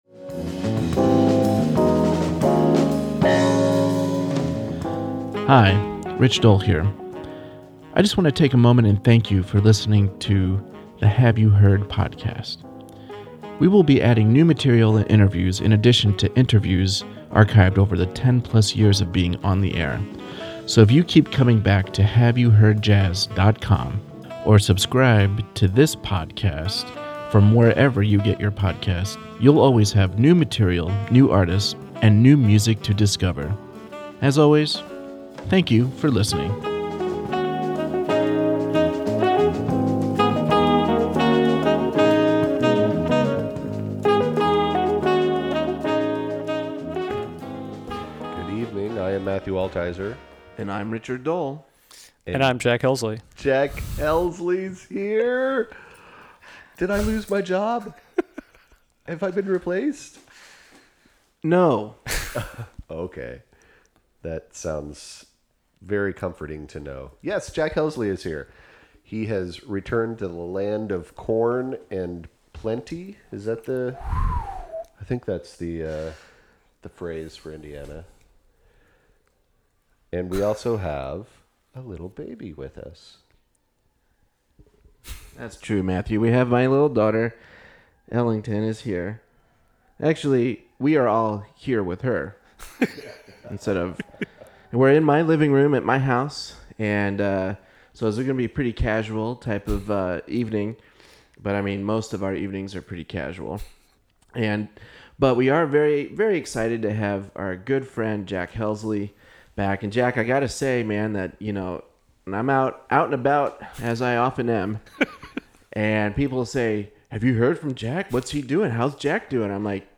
Thankfully our recording equipment was there so we were able to convert our conversation into two full shows!
There is some SEARING and BURNING and BEAUTIFUL music in this first of two podcasts!